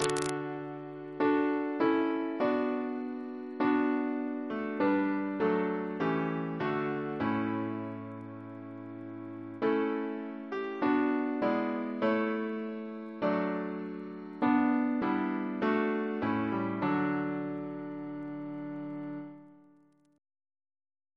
Double chant in D Composer: Richard Woodward, Jr. (1744-1777), Organist of Christ Church Cathedral, Dublin Reference psalters: ACB: 1; ACP: 168; OCB: 60; PP/SNCB: 60; RSCM: 161